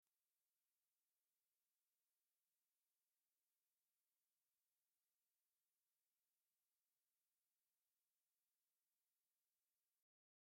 tishina
tishina.mp3